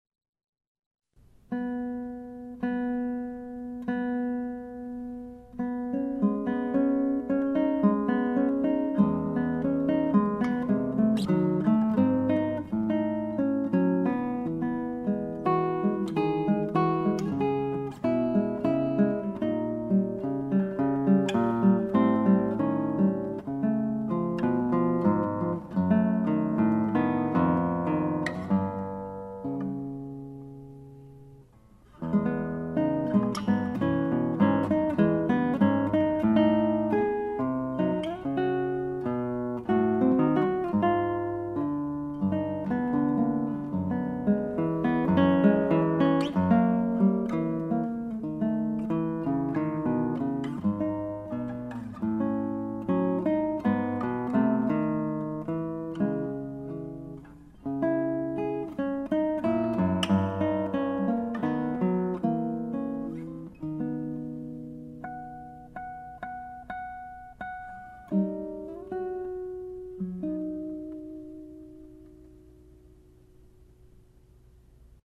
Instrumentation: Flute Guitar